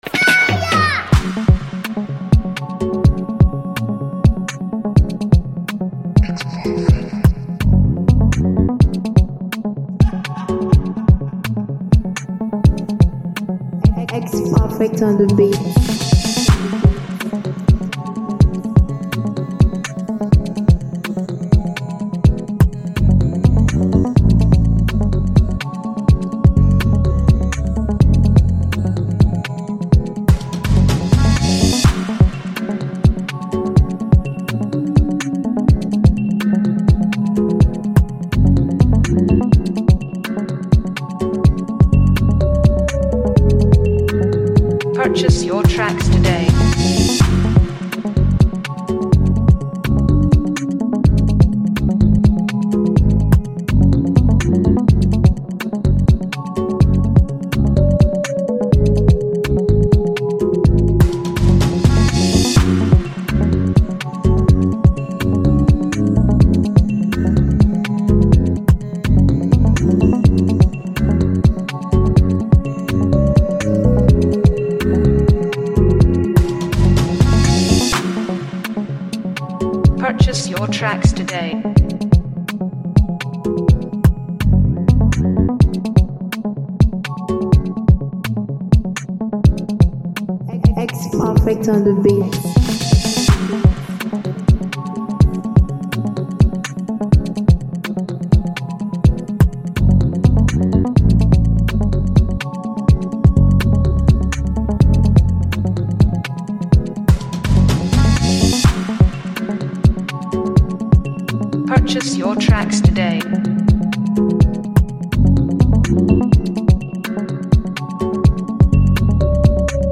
With its infectious energy and unique flair